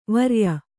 ♪ varya